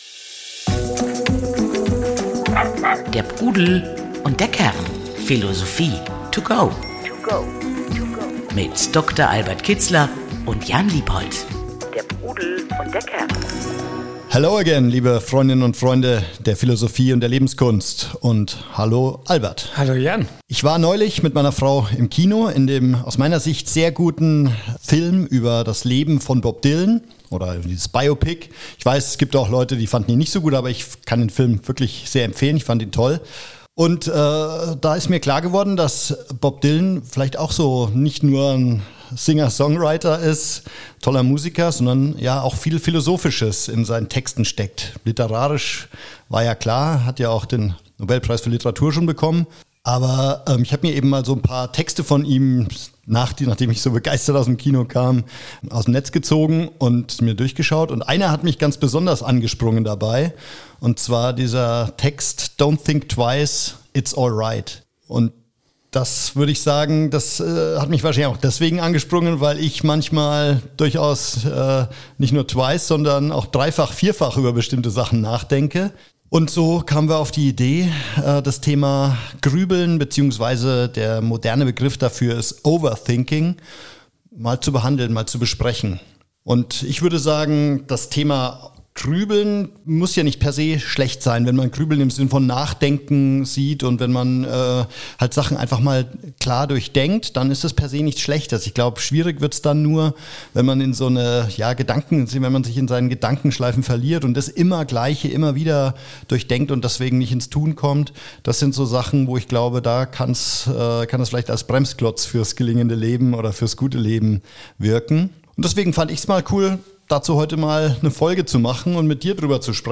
Das alles ist nicht als reiner Dialog geplant.